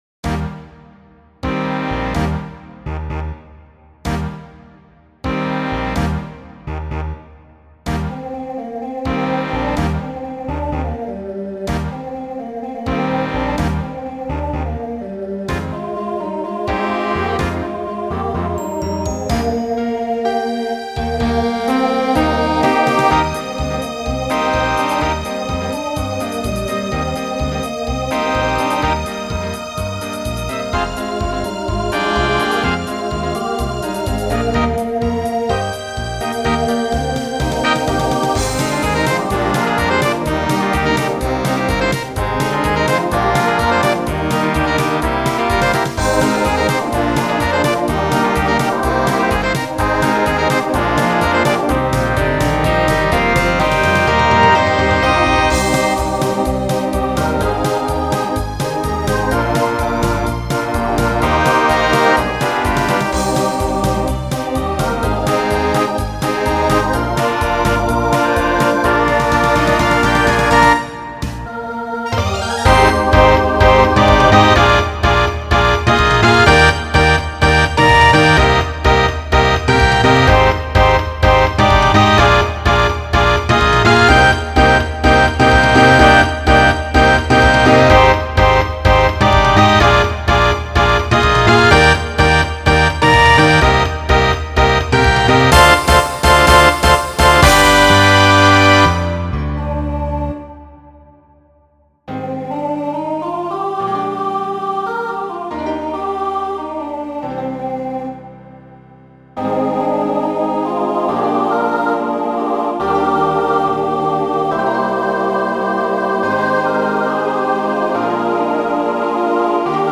Voicing SATB Instrumental combo Genre Broadway/Film
Mid-tempo